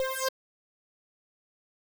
Spacey Synth (SHE).wav